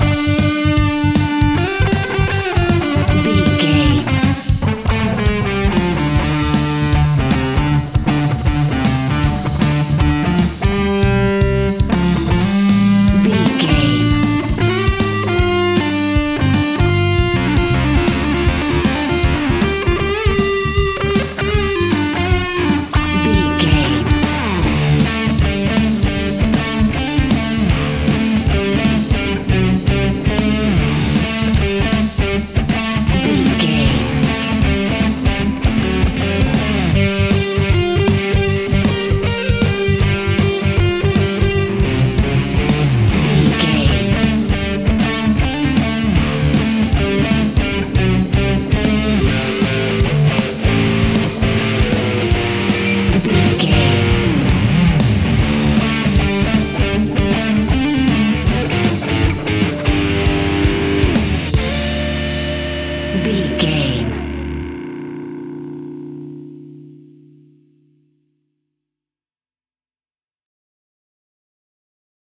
Epic / Action
Aeolian/Minor
hard rock
blues rock
rock instrumentals
Rock Bass
heavy drums
distorted guitars
hammond organ